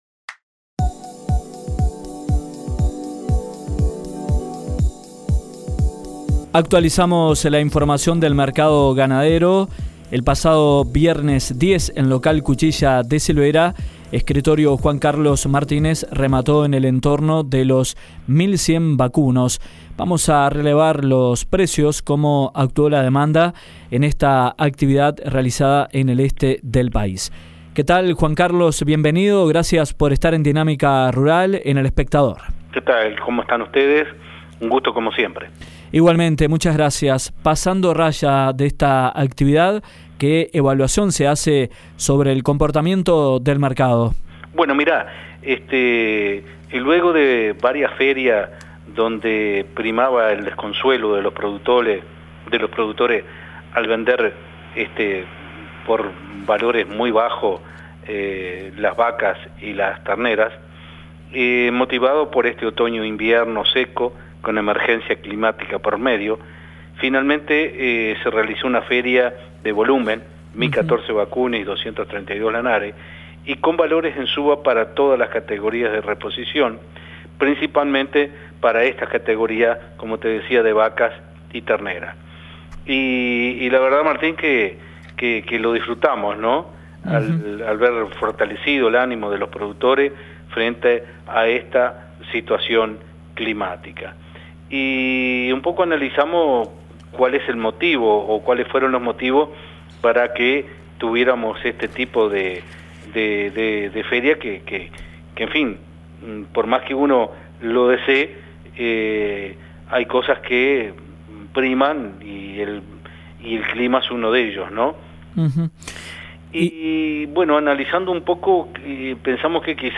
en diálogo con Dinámica Rural